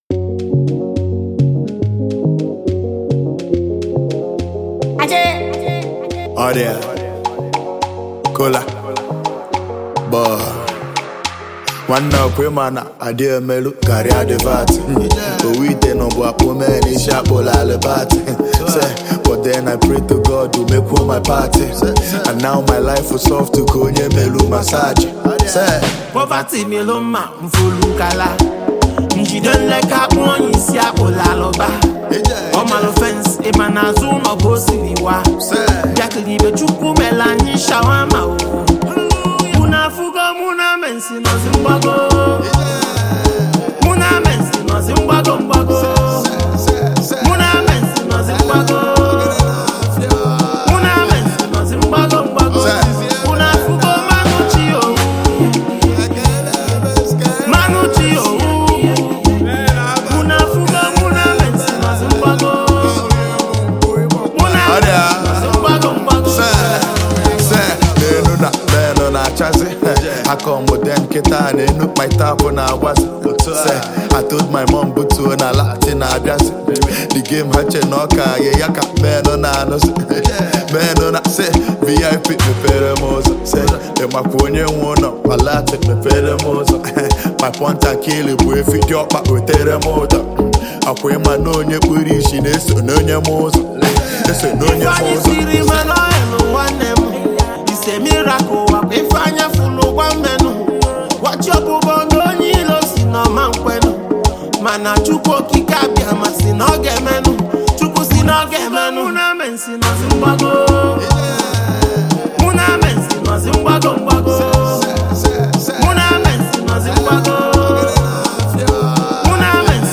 With its upbeat tempo and catchy sounds